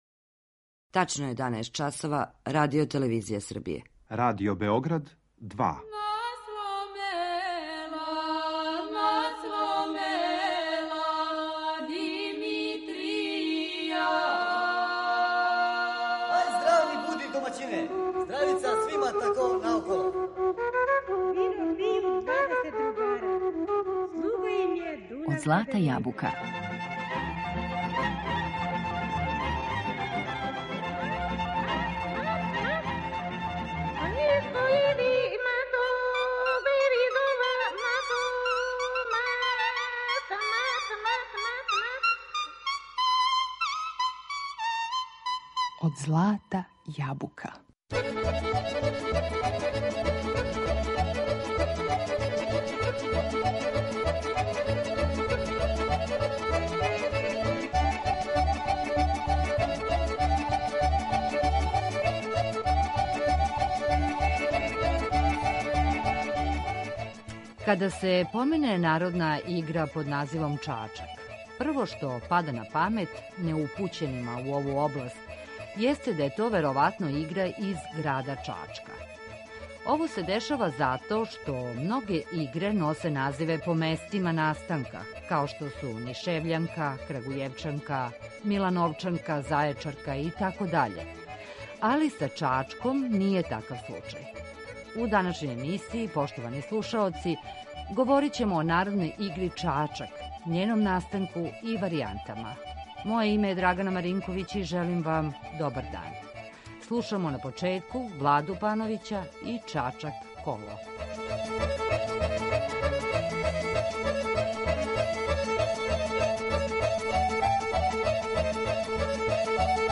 Музичку причу пратимо богатим примерима играчких и мелодијских варијаната ове игре, које су забележене у разним крајевима Србије под називима ‒ бањски чачак, сврљишки, нишки, србијански, ситан чачак, крупан итд.